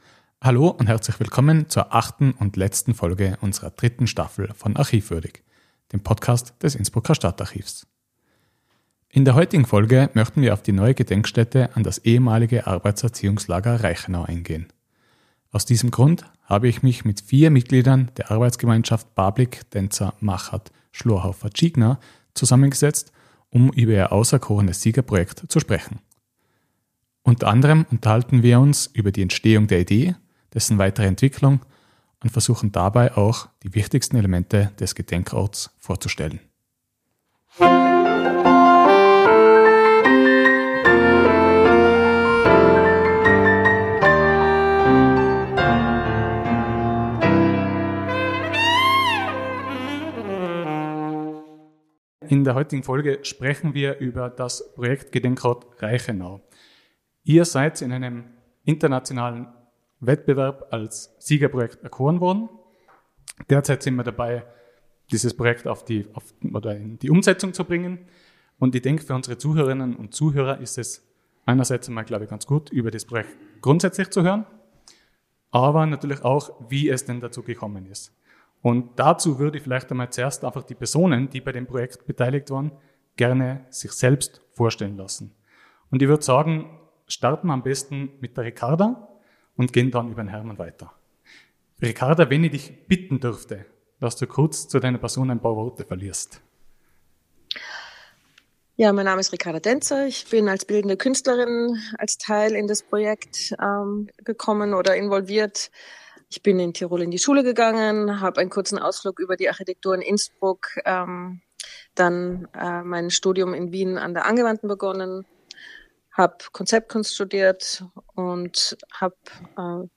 Gespräch mit den Mitgliedern der Arbeitsgemeinschaft über ihr Siegerprojekt des Wettbewerbs für einen neuen Gedenkort in der Reichenau.